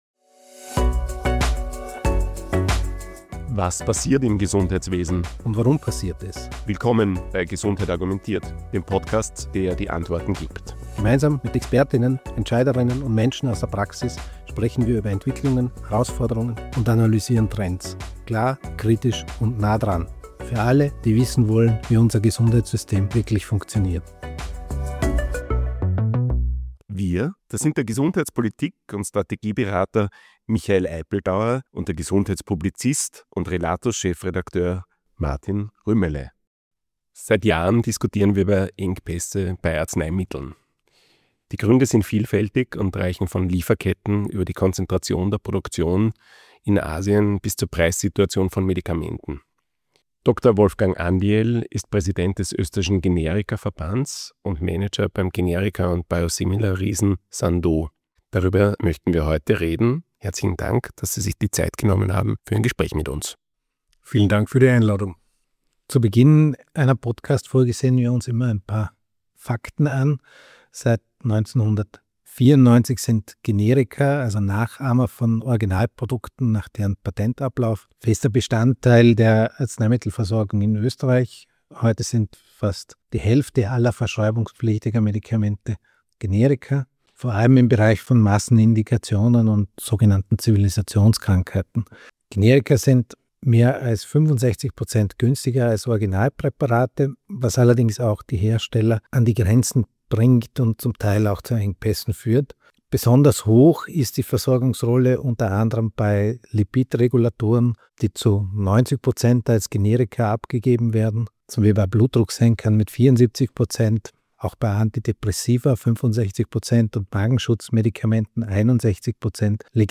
im Gespräch.